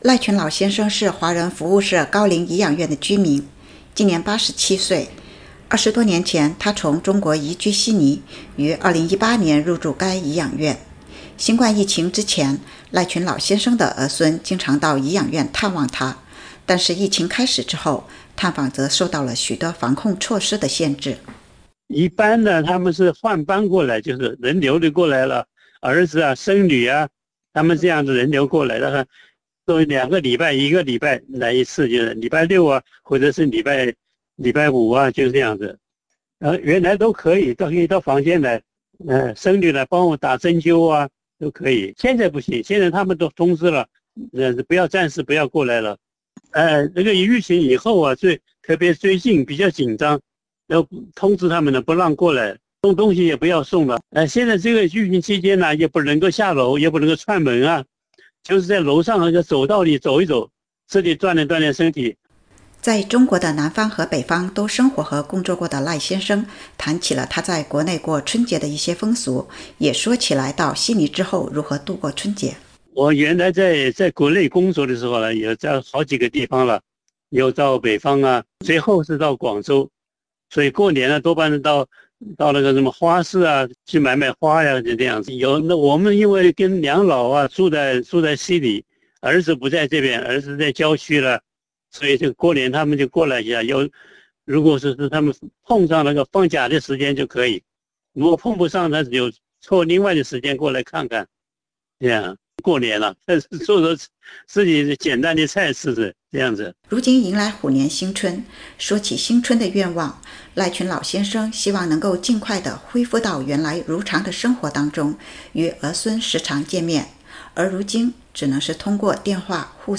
农历新年山河皆暖。澳大利亚疫情中，亲人探访受限，悉尼养老院里的华裔长者如何庆祝虎年新春？（请点击音频收听采访）